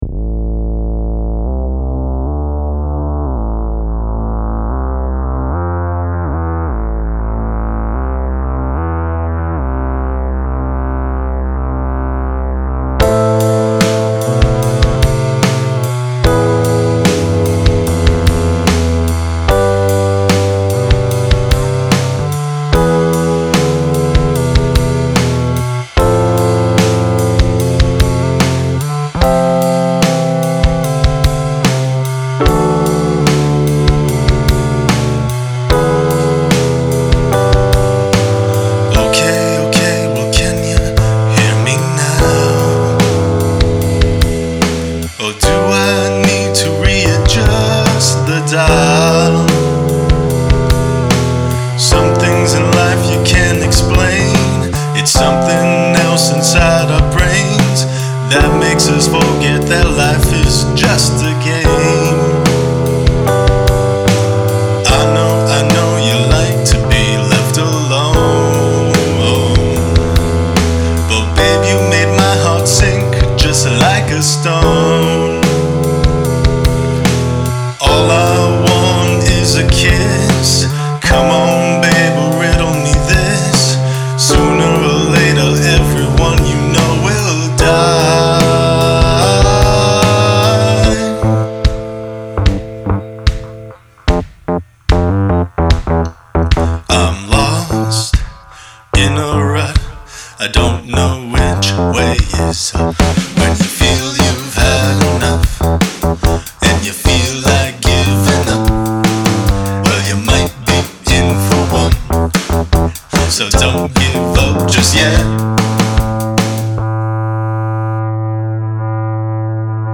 2000's and 2010's alternative rock